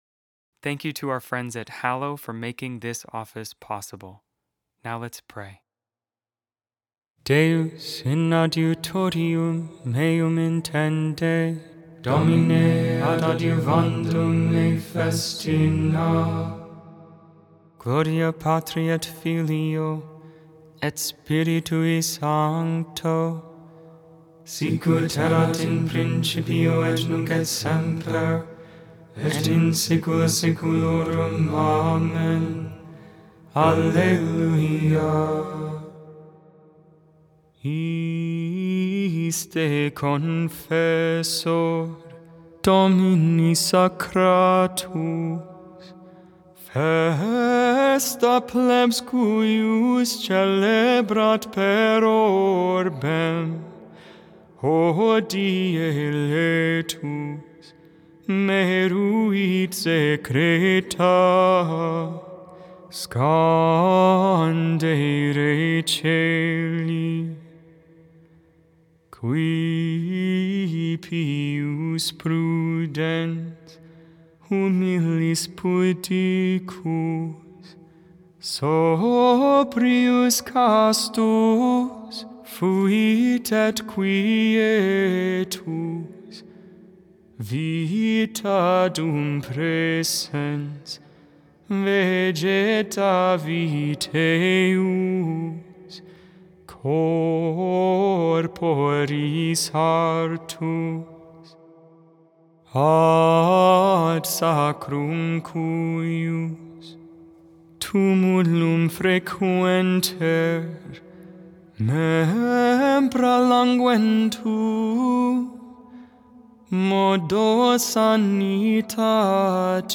Memorial of St. Martin of Tours, BishopMade without AI. 100% human vocals, 100% real prayer.